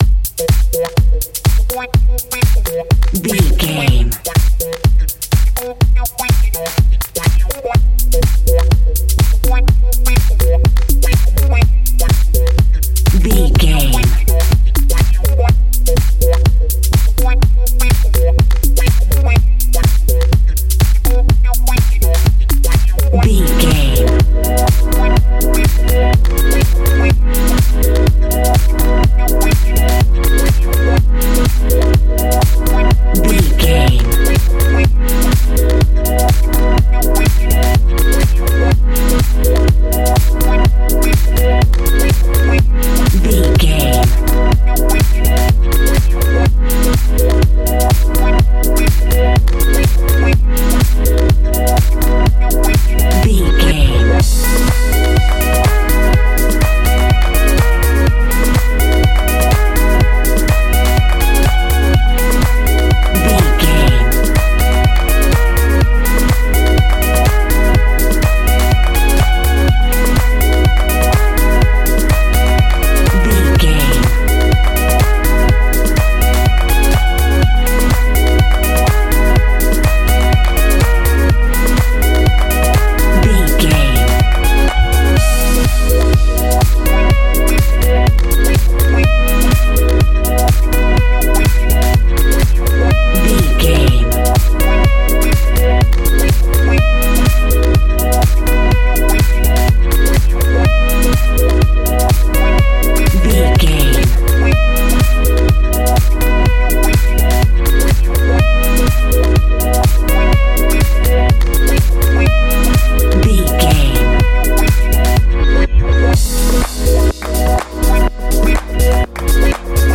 Ionian/Major
groovy
uplifting
energetic
bass guitar
electric guitar
drum machine
funky house
disco house
electro funk
upbeat
synth bass
electric piano
clavinet
horns